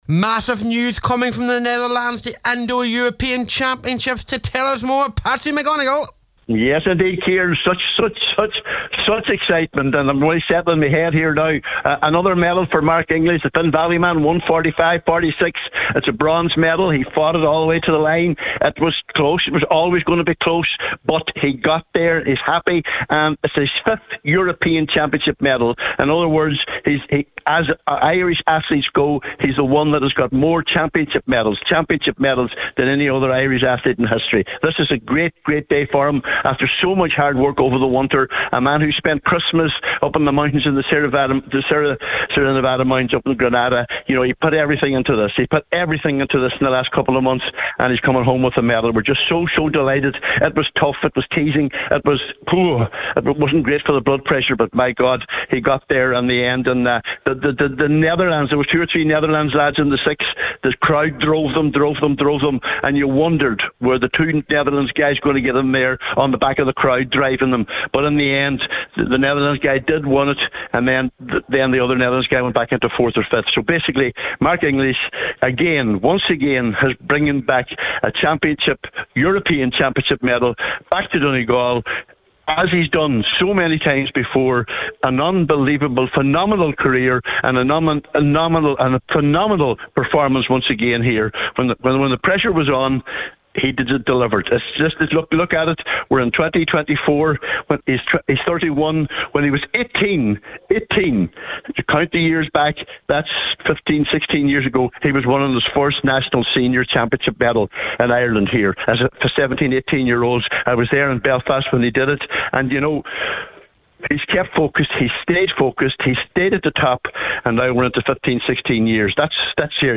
went live for Highland Radio moments after the race…